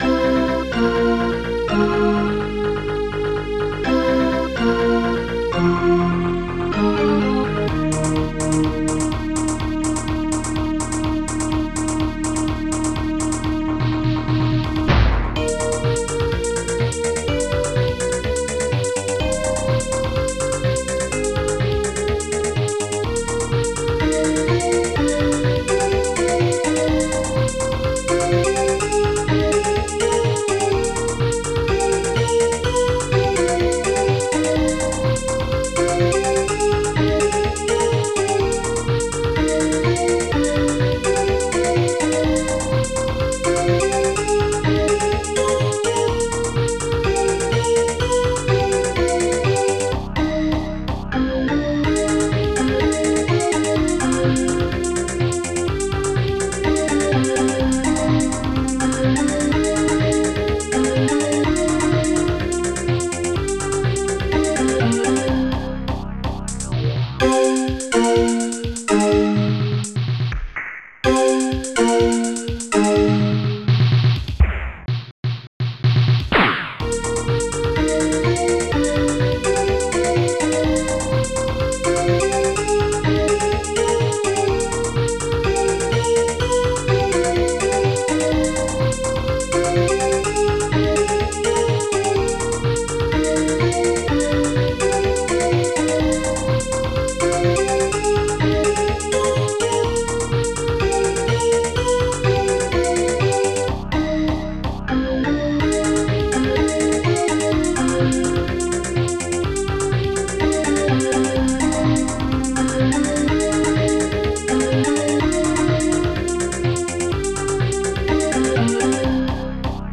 Instruments touch funkbass bassdrum2 snare3 hihat2 strings2 dangerous smash2 heaven shamus BD2/FUK celeste